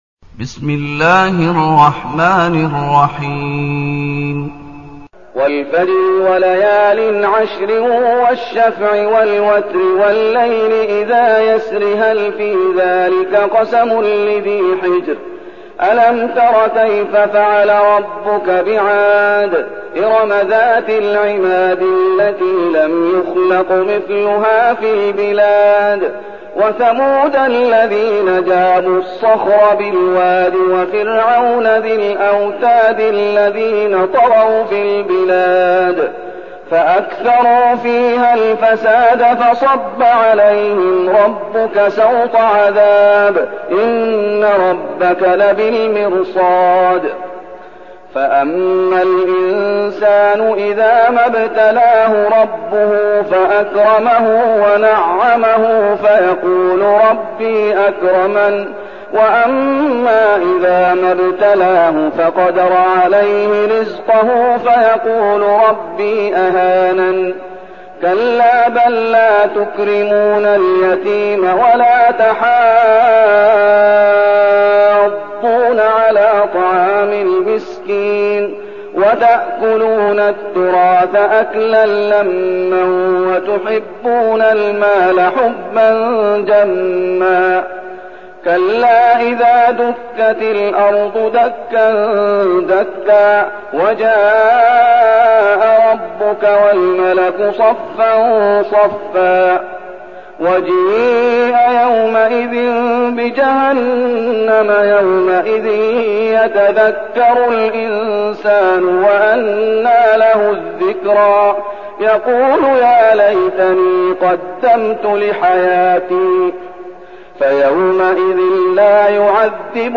المكان: المسجد النبوي الشيخ: فضيلة الشيخ محمد أيوب فضيلة الشيخ محمد أيوب الفجر